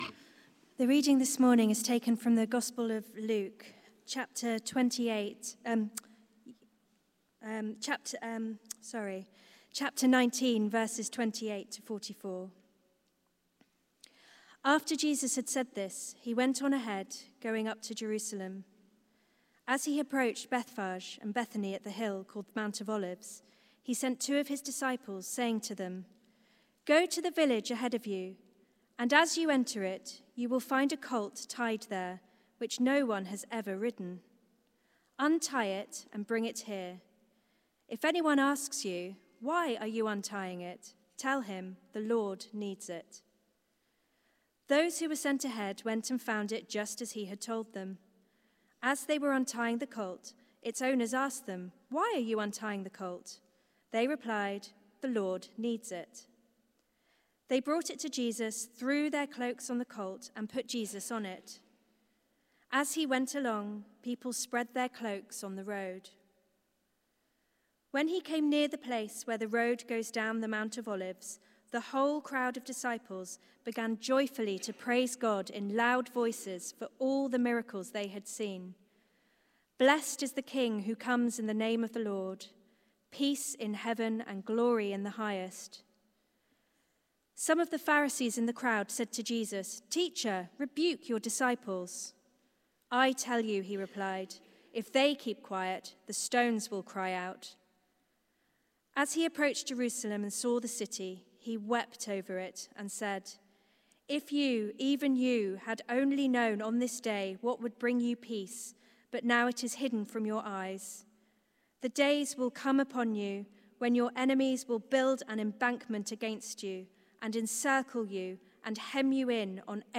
Media for Service (10.45) on Sun 02nd Apr 2023 10:45
Series: Palm Sunday Theme: Blessed is the King of Israel Sermon